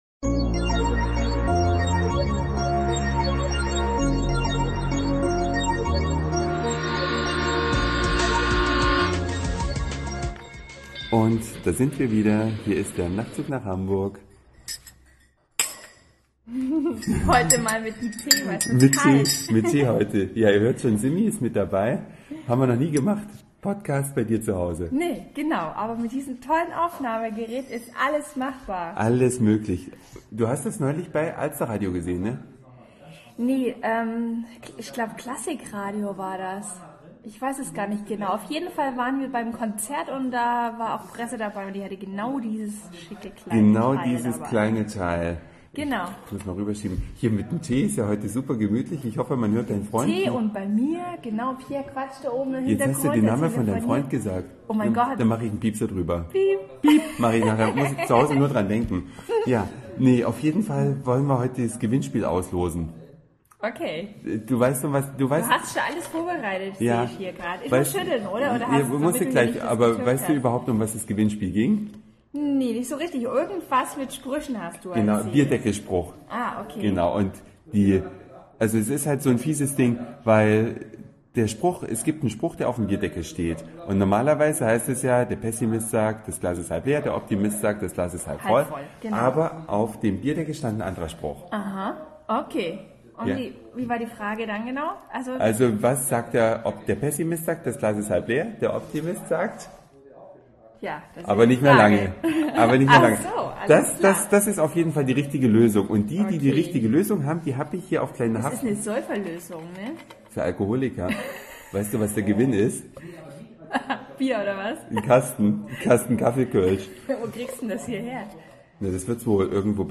"Hintergrundgeräuschen" :-) Den Podcast kostenlos bei iTunes